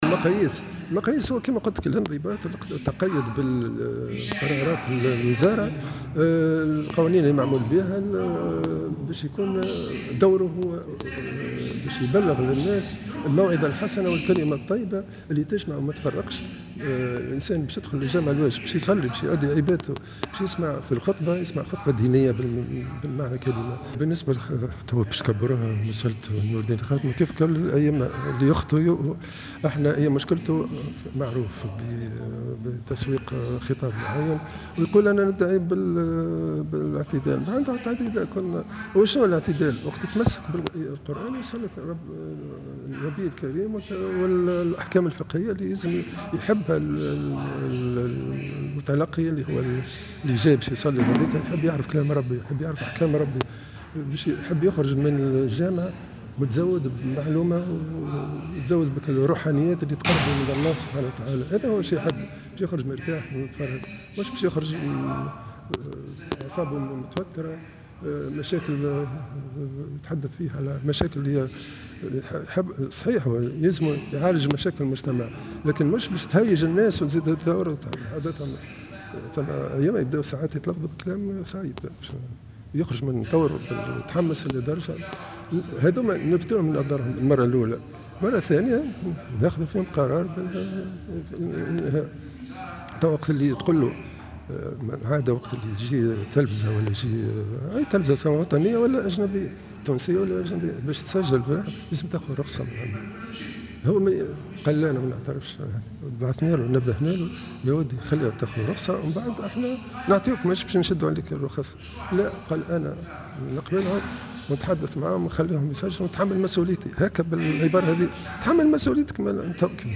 واوضح في تصريح إعلامي على هامش الملتقى الوطني لمرشدي الحجيج الخميس بتونس انّ الامام أو الإطار الديني متعاقد مع الوزارة وليس موظف لديها مما يخول لها الحق في إعفائه دون تقديم تبرير ازاء قرارها.